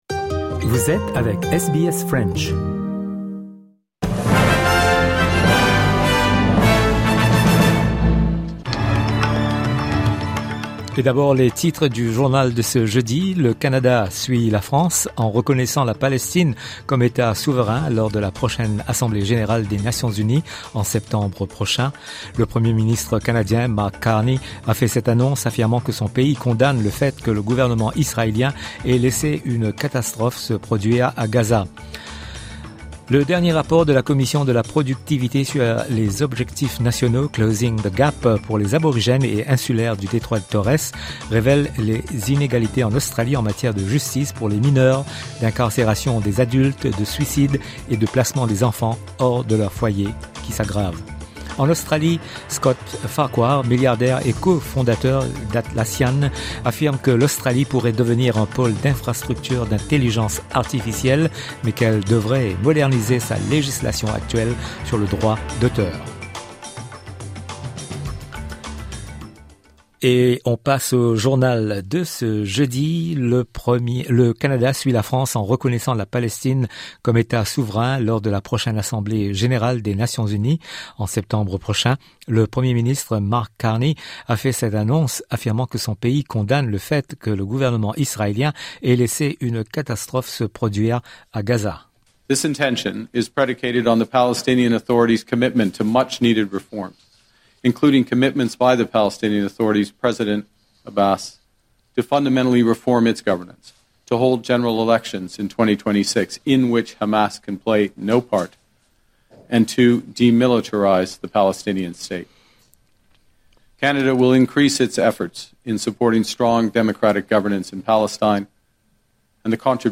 Le journal du 31/07/2026: l'intention du Canada de reconnaître l'État de Palestine.